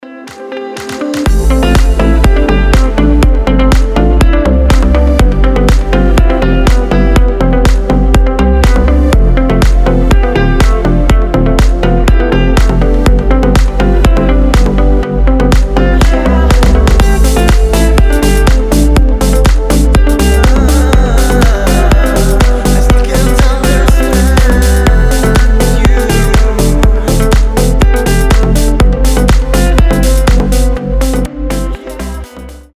• Качество: 320, Stereo
гитара
deep house
красивая мелодия